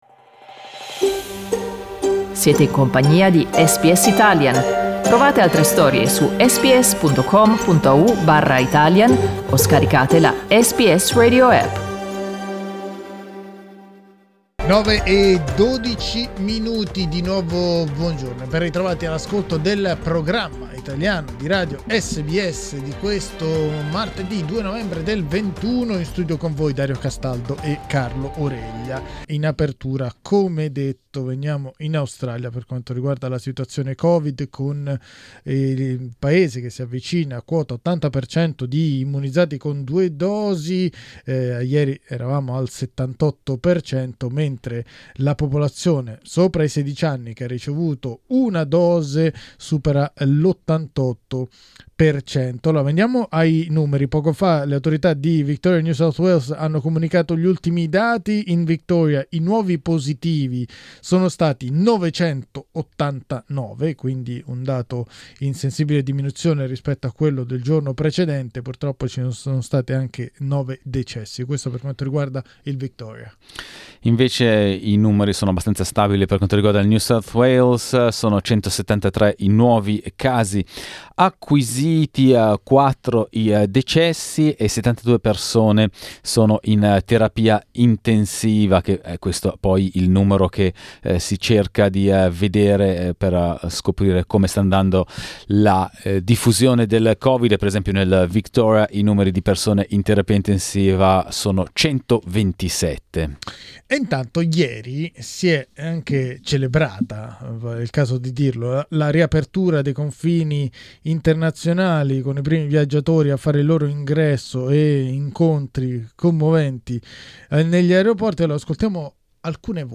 Ascolta il servizio di SBS Italian.